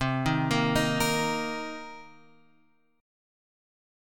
C Minor 7th